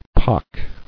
[pock]